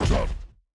boss_attack.ogg